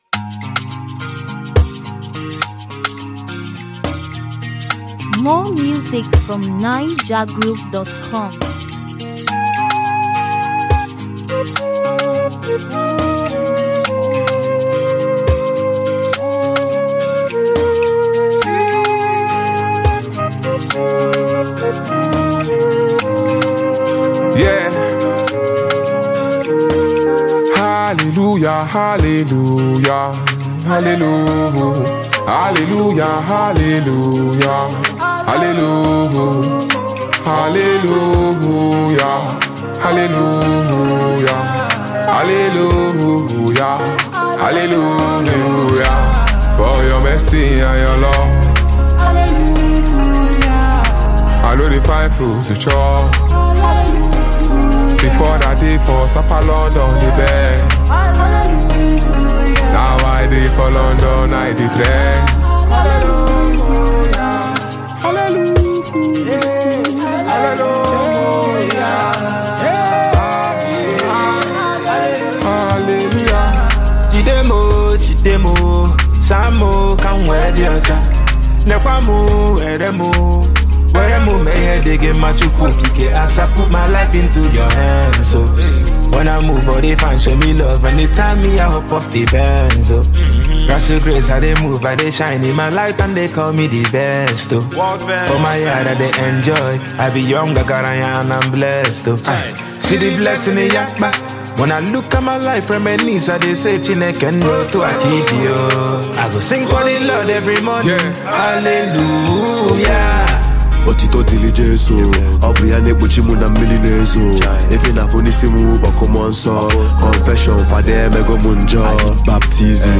Albums, Latest, Naija-music, African-music